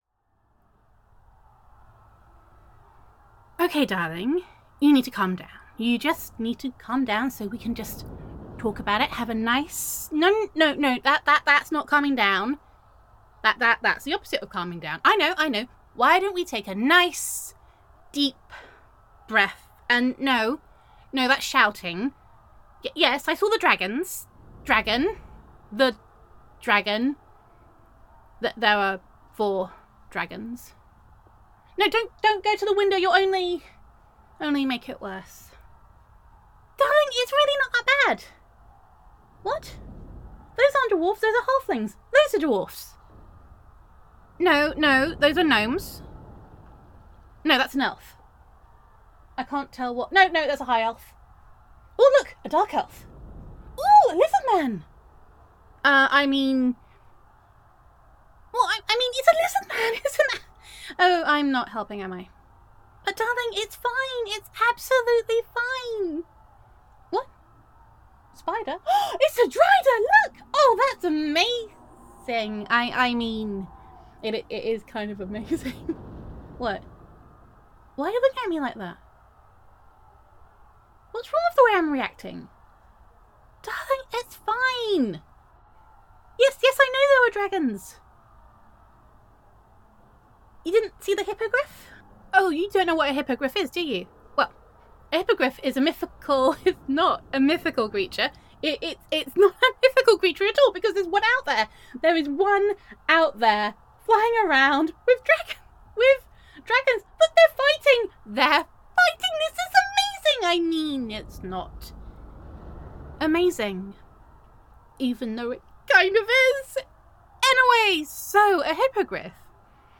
BEWARE THE SQUEAKY HIGH PITCHED NERDGASM!!!
[F4A] Second Best Day Ever [Unicorns Are Cool][Dungeons and Dragons Piss Take][Short People][Weaponry][Board Games][Nerdery][Inappropriate Reactions to Dangerous Situations][I Am Nerdy and I Know It][[Girlfriend Roleplay][Nerdgasm][Gender Neutral][Having a Super Nerd Girlfriend Plays off When a Giant Magic Portal Opens Up in the Sky]